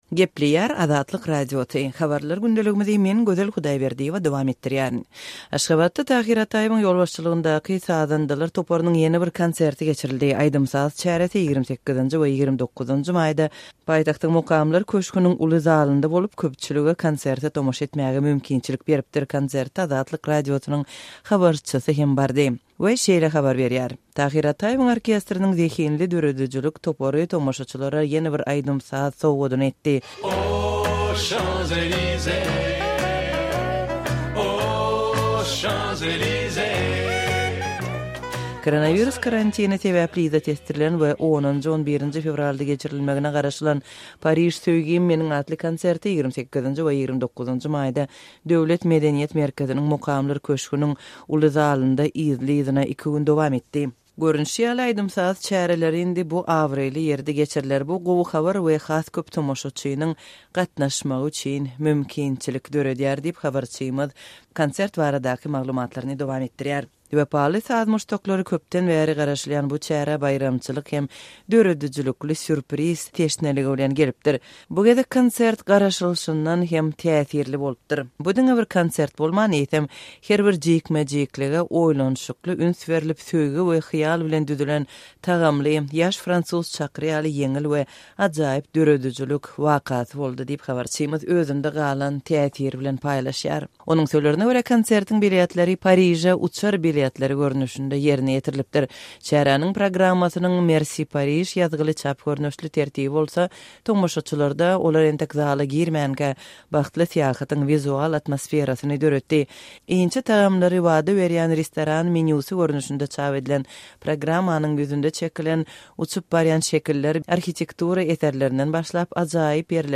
Aýdym-saz çäresi 28-nji we 29-njy maýda Mukamlar köşgüniň uly zalynda bolup, köpçülige konserte tomaşa etmäge mümkinçilik berdi. Konserte Azatlyk Radiosynyň habarçysy hem bardy.